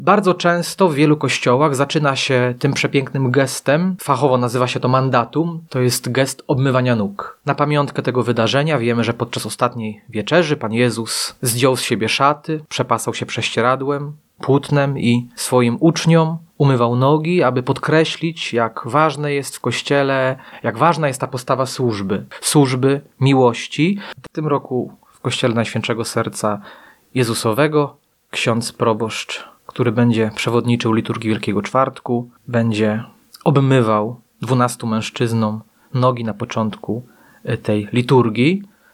Mówi ksiądz